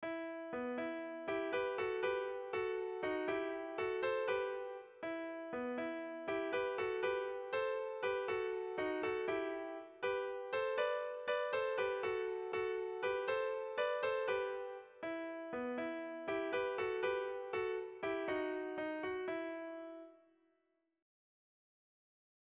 Erlijiozkoa
Erritmo polita.
Zortziko txikia (hg) / Lau puntuko txikia (ip)
A1A2BA3